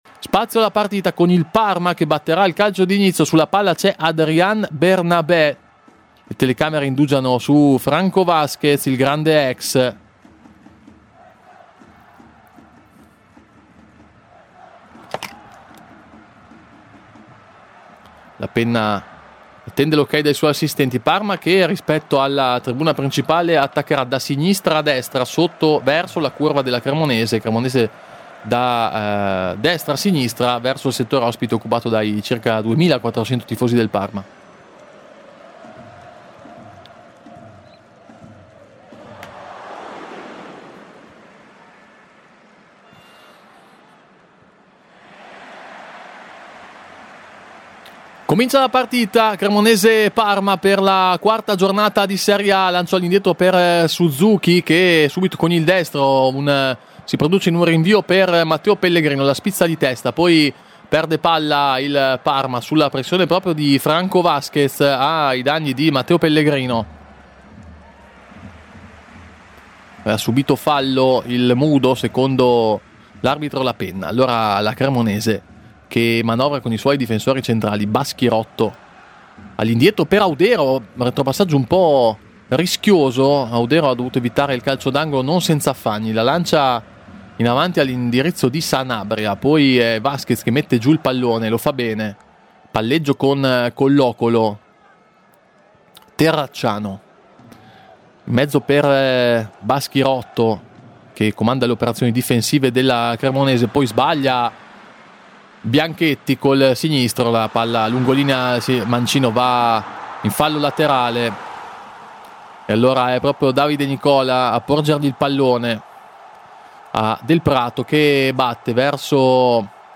Radiocronache Parma Calcio Cremonese - Parma - 1° tempo - 21 settembre 2025 Sep 21 2025 | 00:51:34 1x 00:00 / 00:51:34 Subscribe Share RSS Feed Share Link Embed